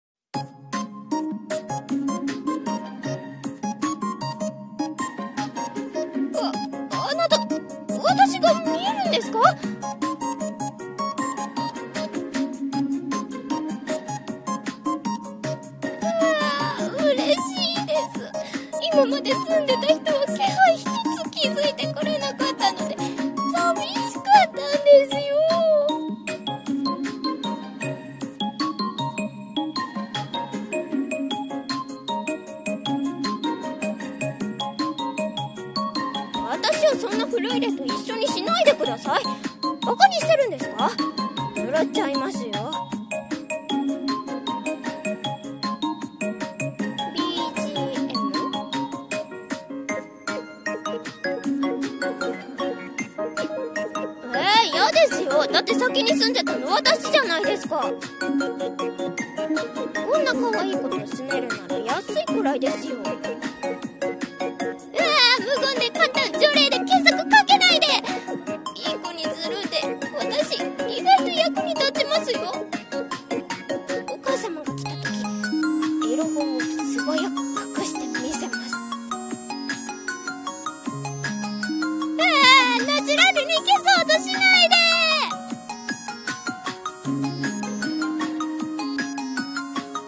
【声劇台本】幽霊と同棲【掛け合い】